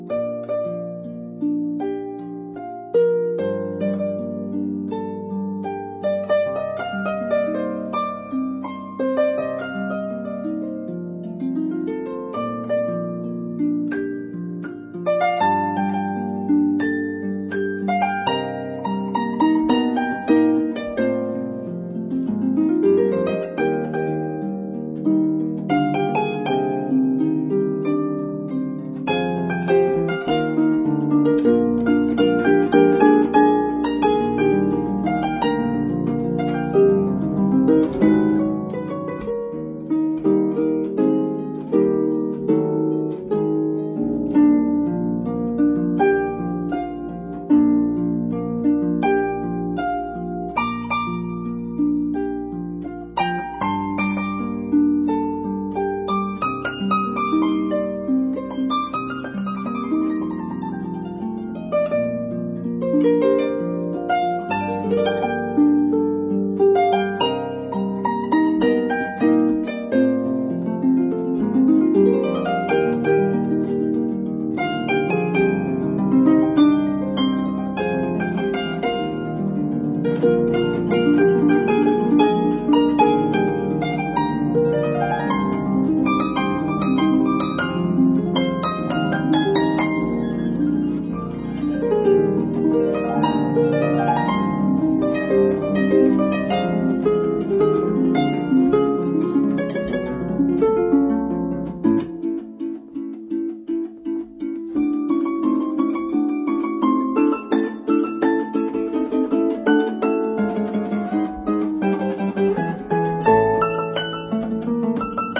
不看歌詞，只聽音樂，覺得很美幻。
這個系列，把古典與流行交叉演奏。
卻很像是現代精準的機關槍，敢連發，還能全命中。